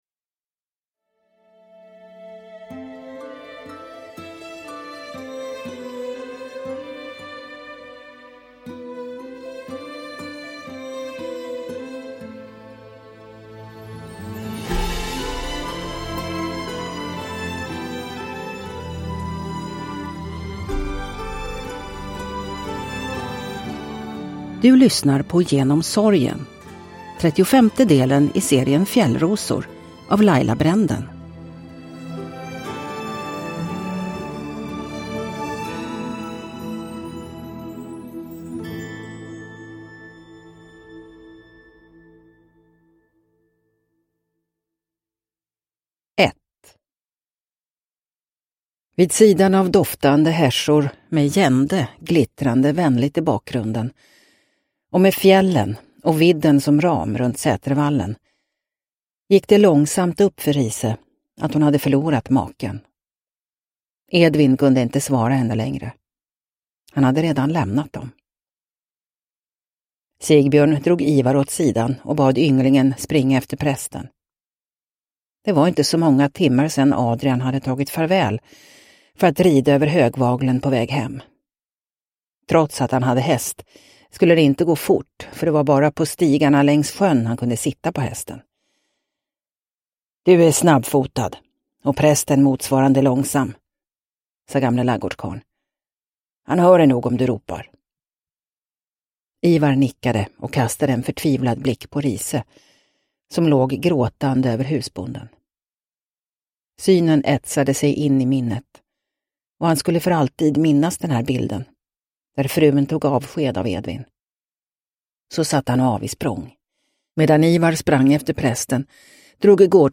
Genom sorgen – Ljudbok – Laddas ner